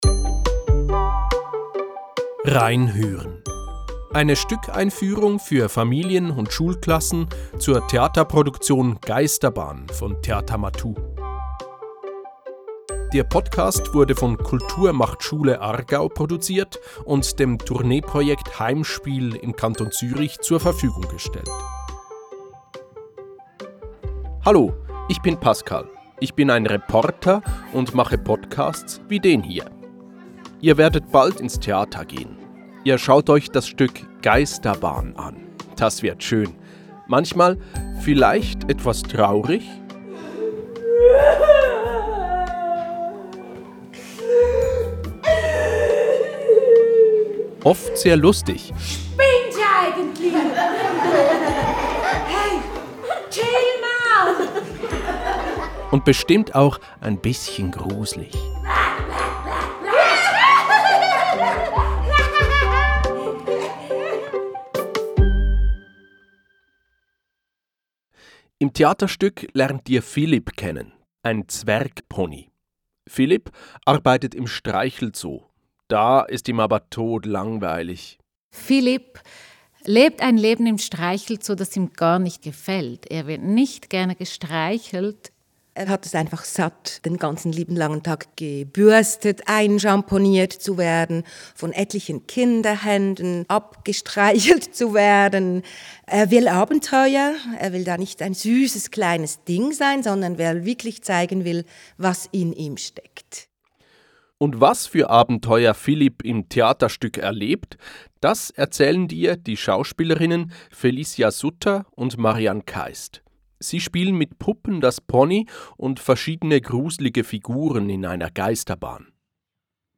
Podcast – eine Stückeinführung für Schulklassen